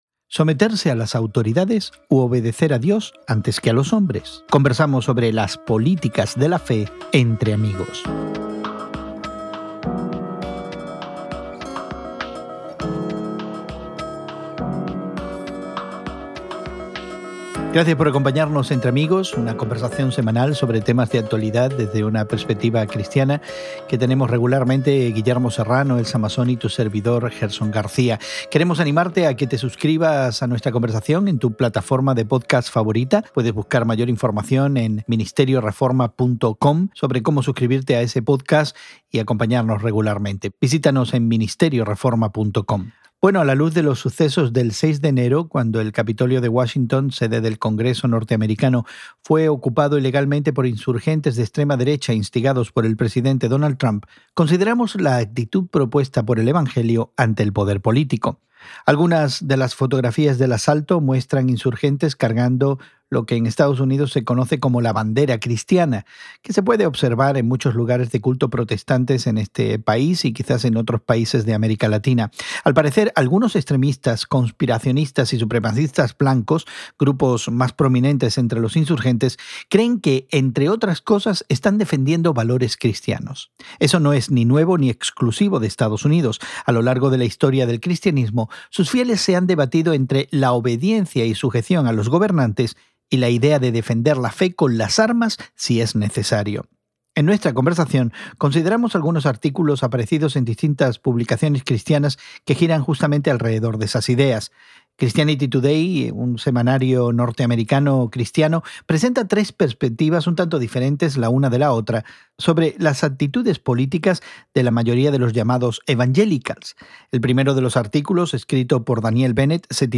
Las politicas de la fe Entre Amigos: Vol. 8, Episodio 2 Escucha la conversación Entre Amigos.